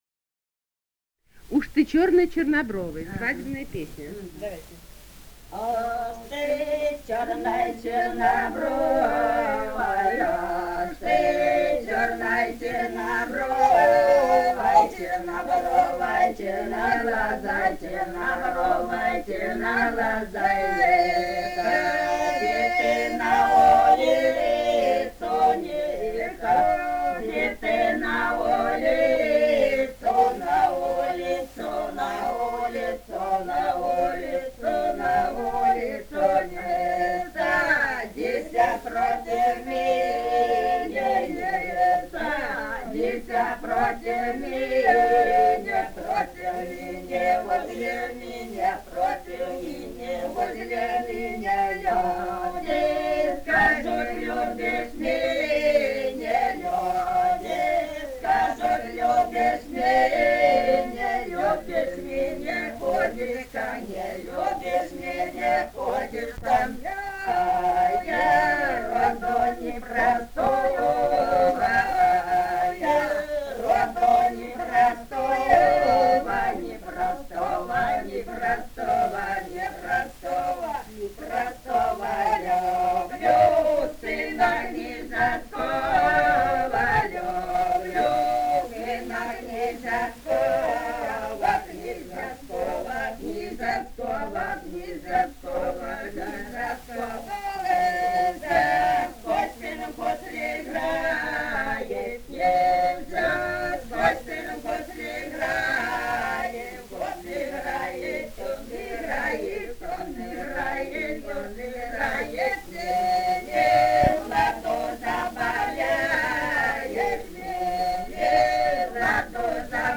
Этномузыкологические исследования и полевые материалы
Ростовская область, ст. Вёшенская, 1966 г. И0938-11